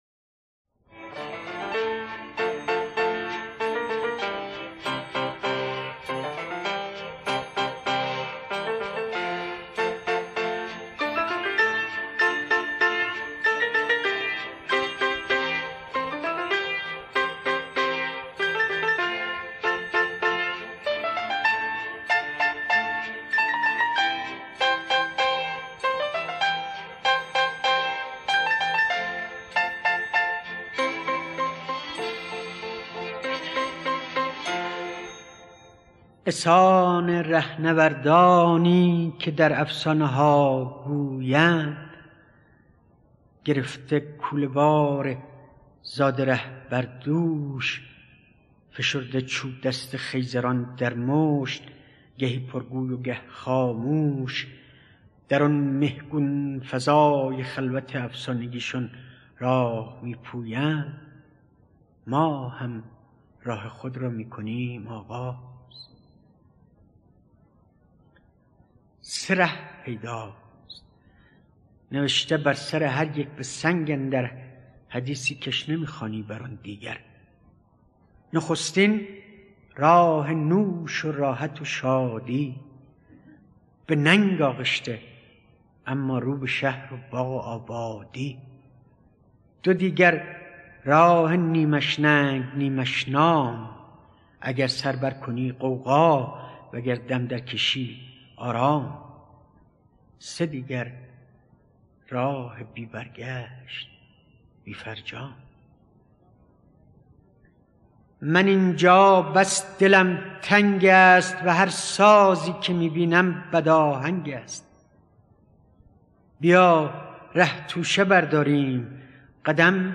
دانلود دکلمه چاووشی با صدای مهدی اخوان ثالث با متن دکلمه